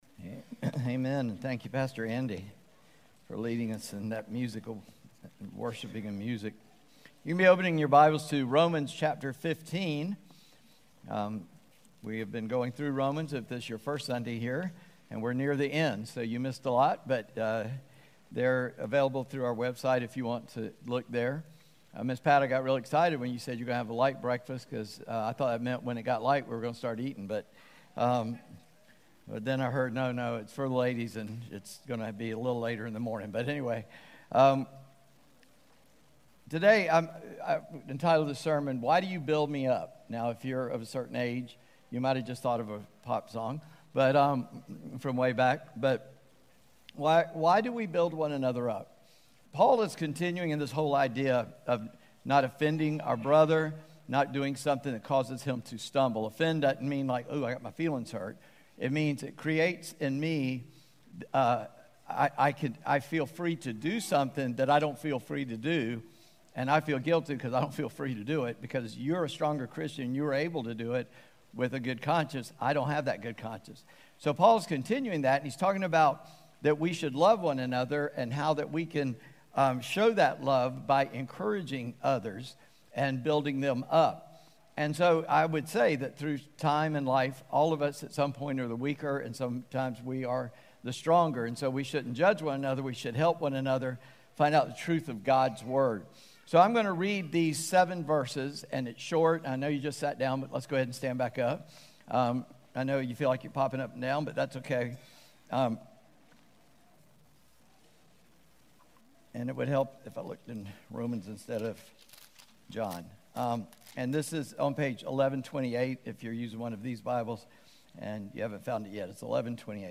Discipleship Sunday Service Romans https